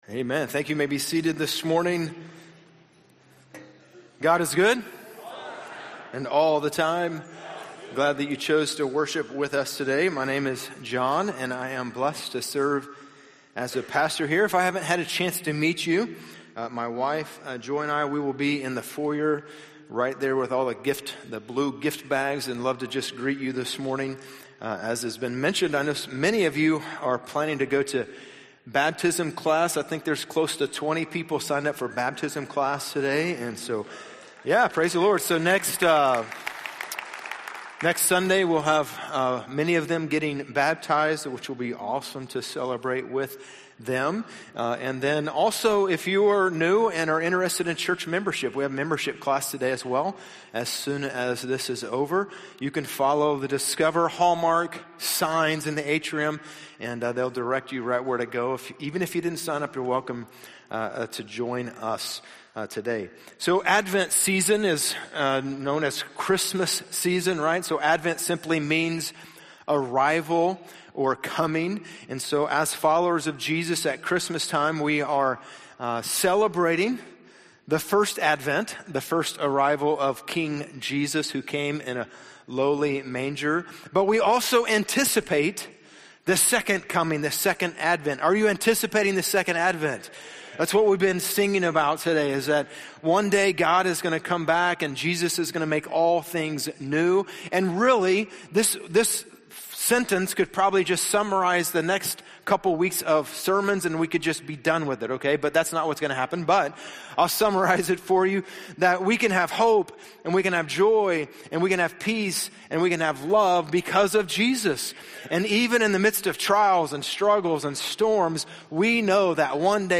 The Light Has Come #2 - Peace - Sermons - Hallmark Church